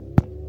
beeb kick 26 (machine)
Tags: 808 drum cat kick kicks hip-hop